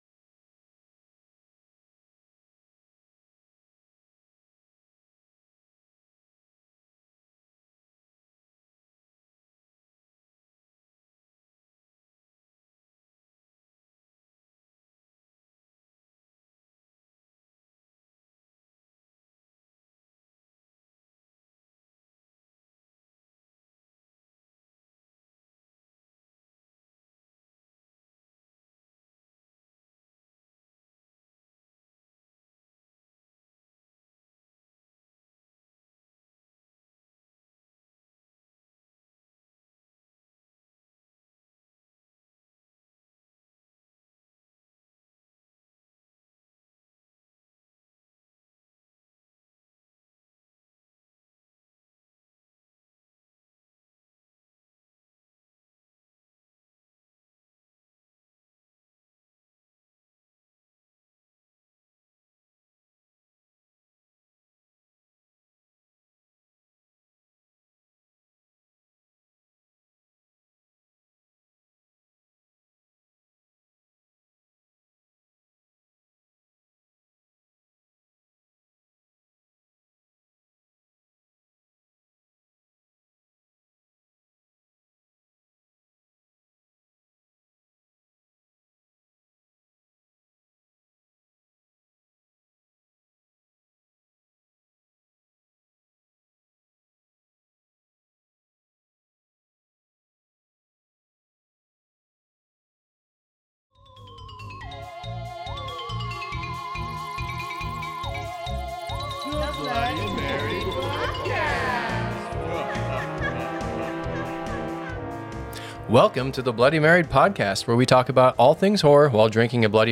a totally rad couple, as they have a heart-to-heart about all things horror while drinking Bloody Marys.